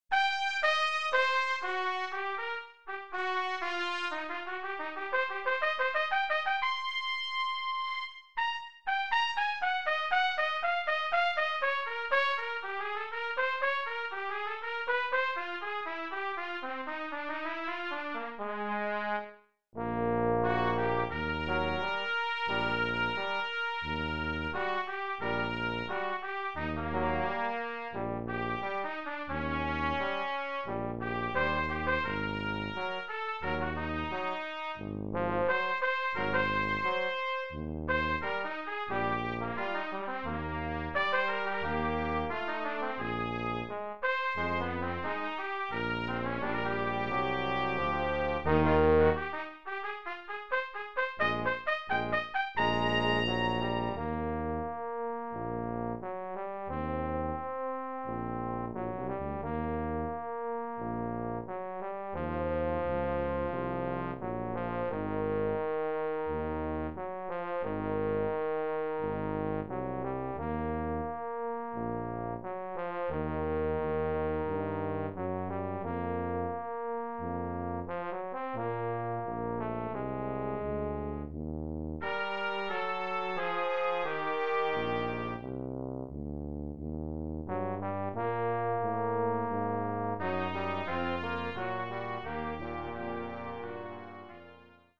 Brass Trio TTT